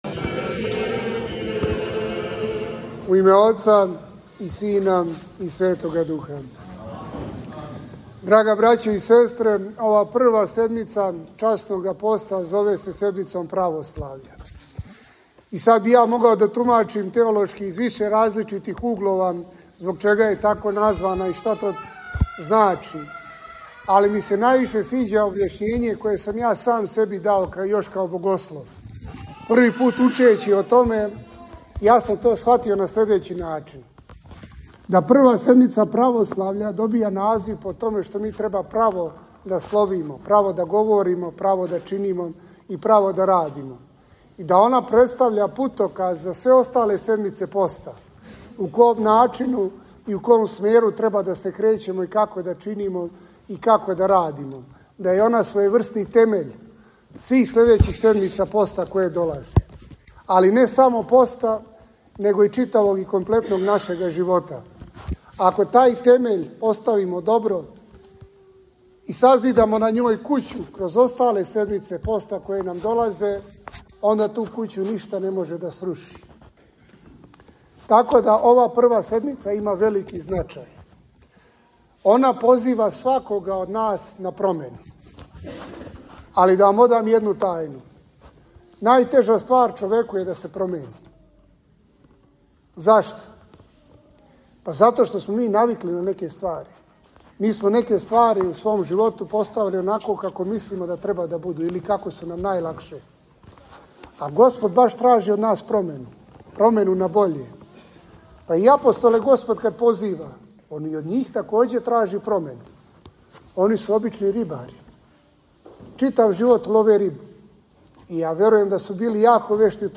Беседа у Недељу православља